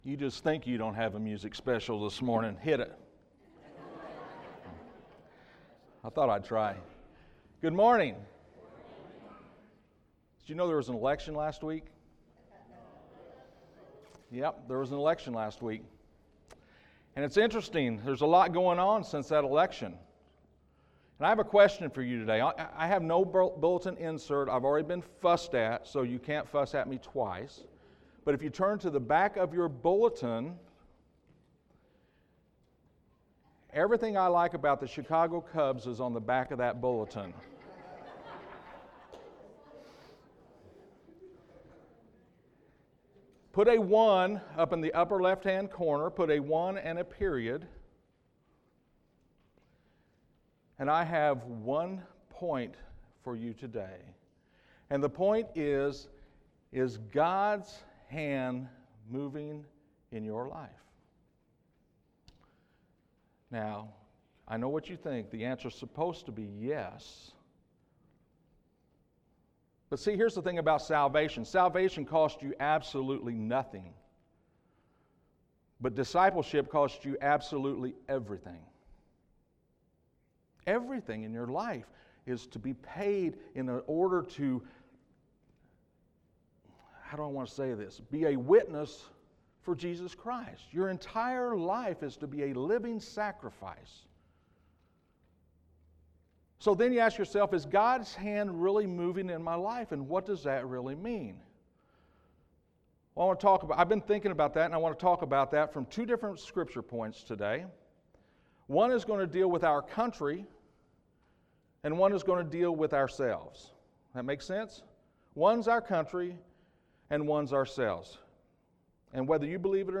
Sermons Archive - Page 9 of 35 -